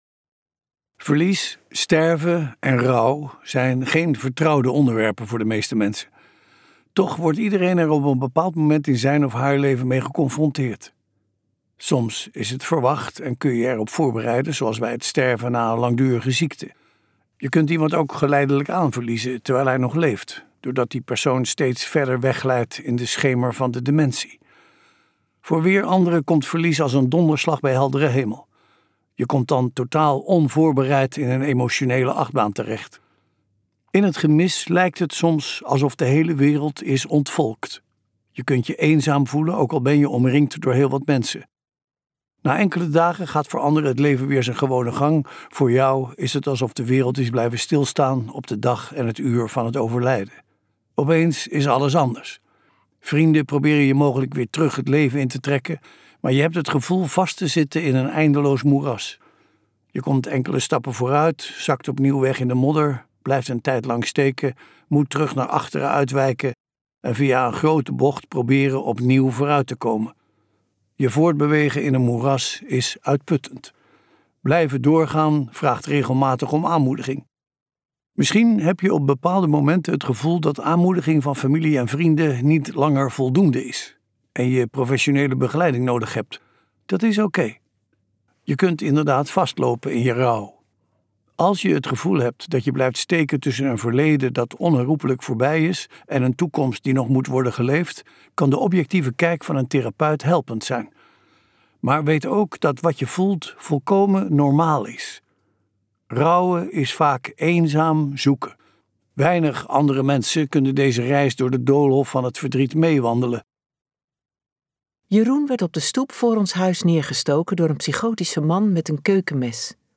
Ambo|Anthos uitgevers - Die ene die er niet meer is luisterboek